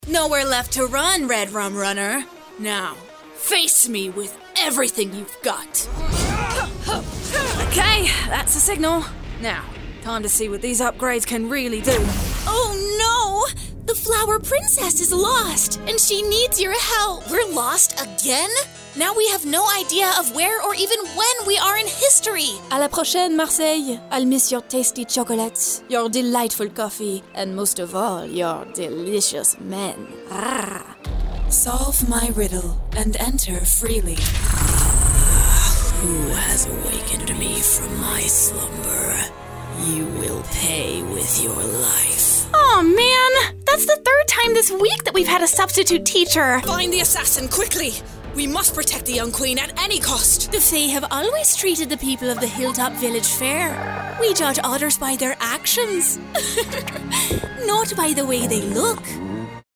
Bande-démo jeux video
10 - 40 ans - Contralto Mezzo-soprano